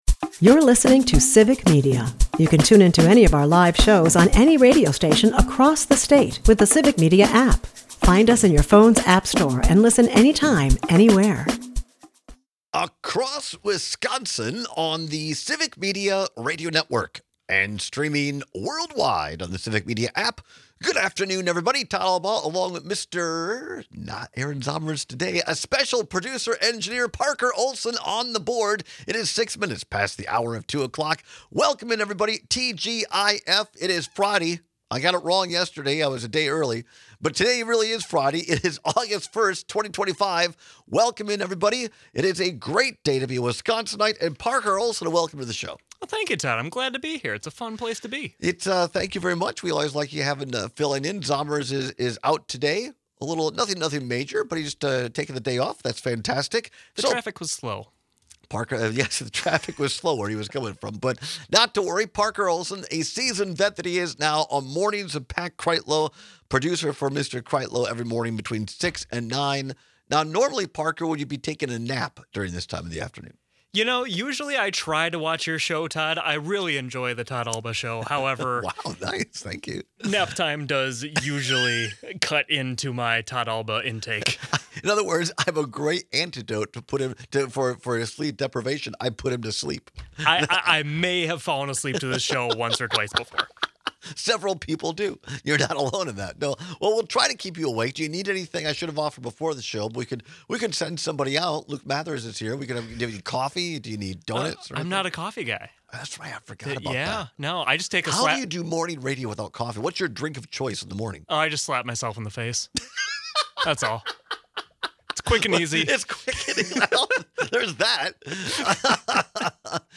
We look back on a Mr. Rogers clip in Congress in 1969. Mr. Rogers advocated for how important his show is to educate kids about things like getting a haircut or handling anger.